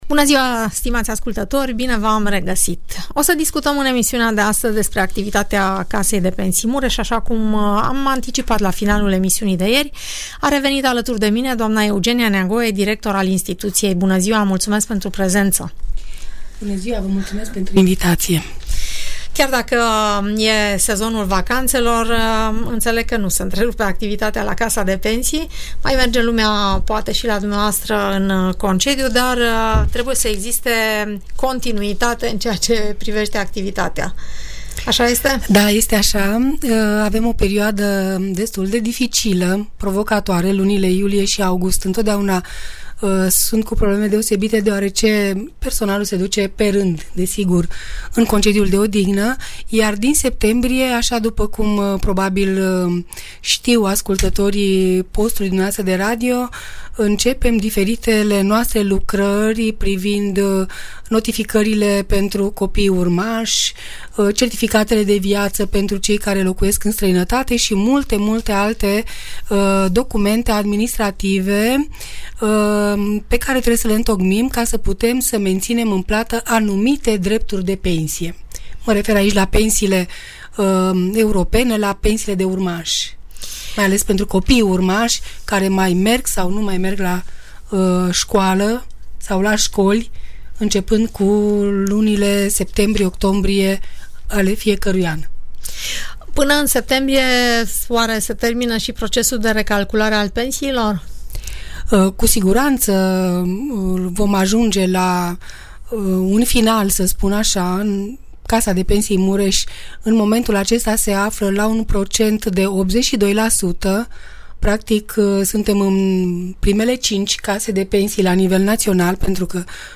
Audiență radio cu întrebări și răspunsuri despre toate tipurile de pensii, în emisiunea „Părerea ta” de la Radio Tg Mureș.